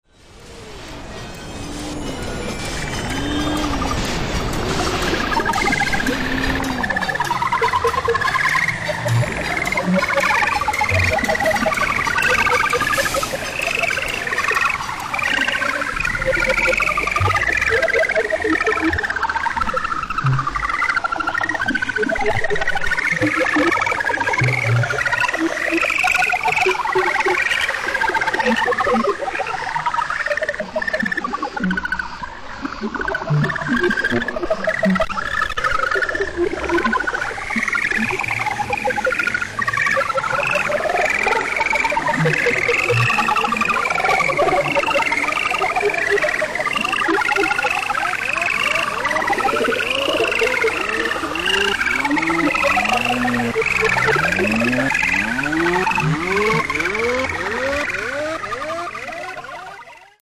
Ultimately we ended up with a three part piece, a sonic journey that took us from the city (city sounds) to the country (animal and insect sounds) and finally to outer space (warped city sounds and sound effects).
citytocountry.mp3